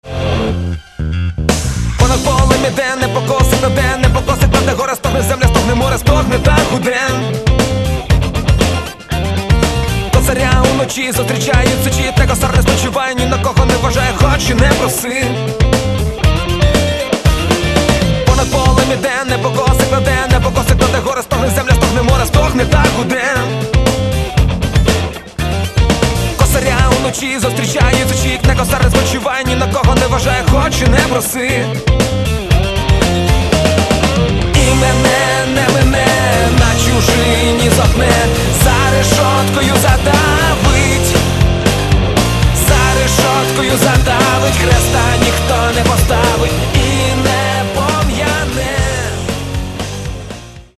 Хип-хоп